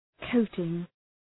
{‘kəʋtıŋ}